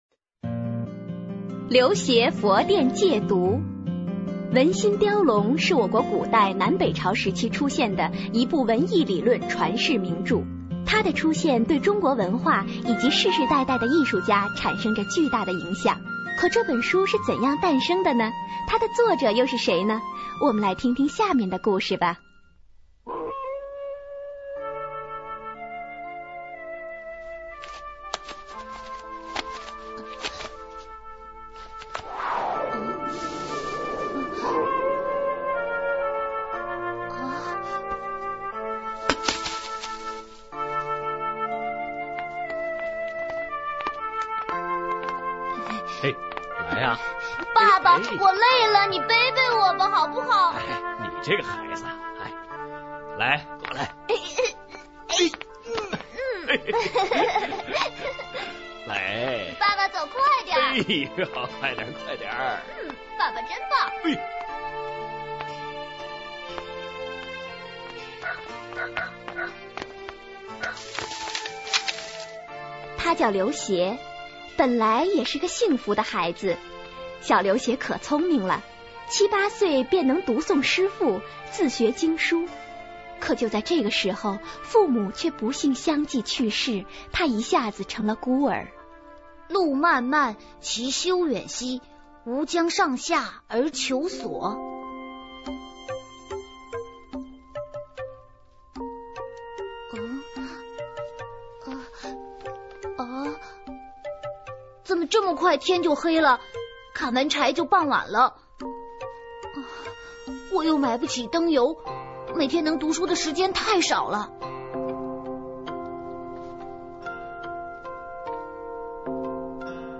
首页>mp3 > 儿童故事 > 刘勰佛殿借读